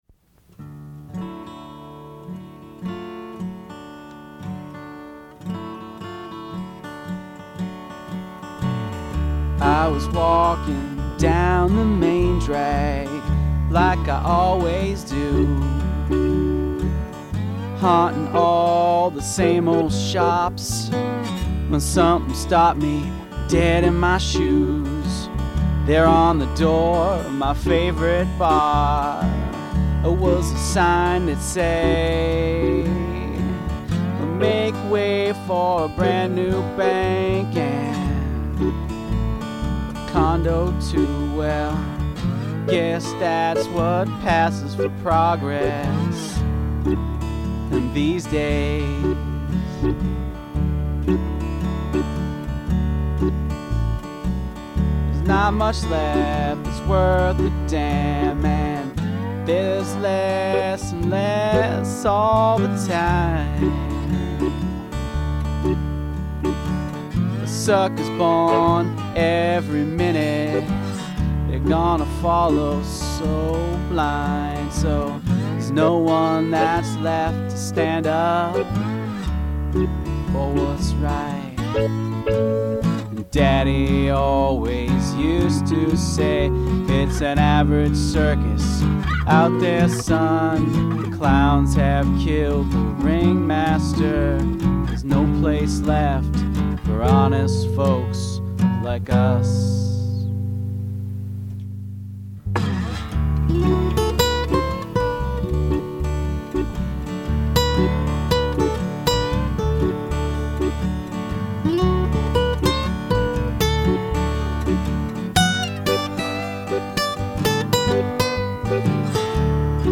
Use something from your garbage bin as an instrument